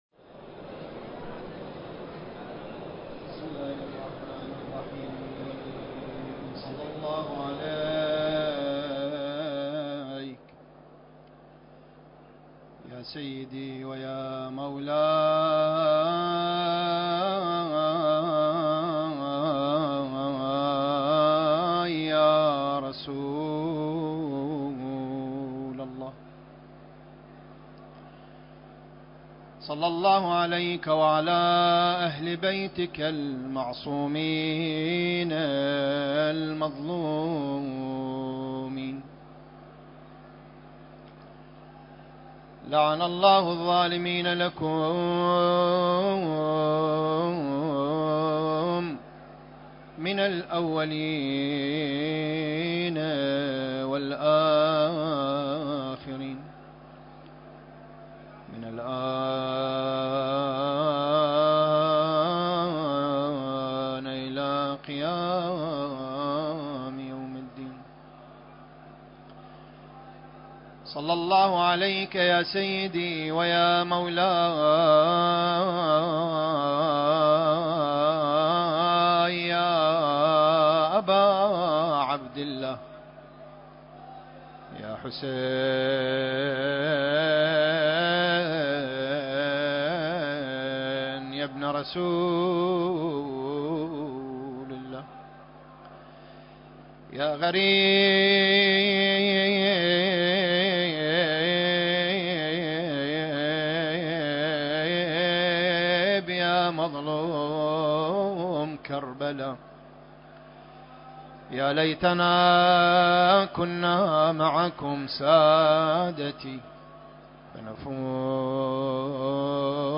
المكان: العتبة الحسينية المقدسة